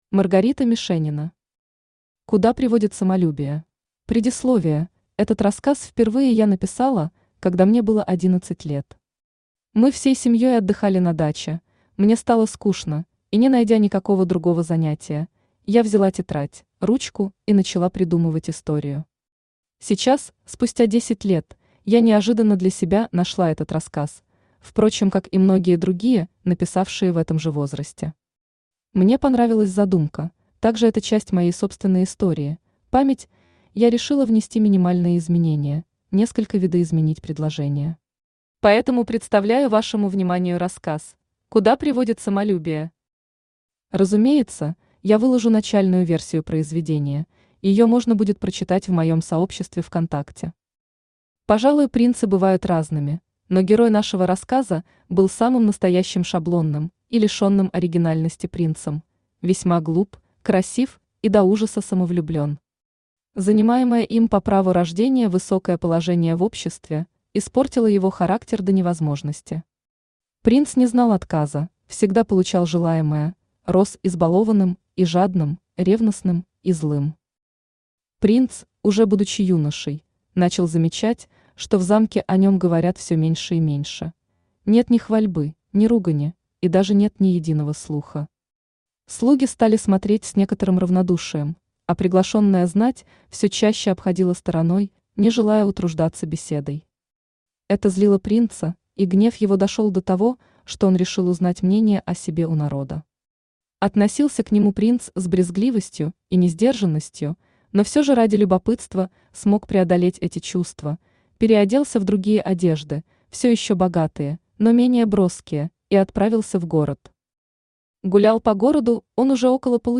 Автор Маргарита Мишенина Читает аудиокнигу Авточтец ЛитРес.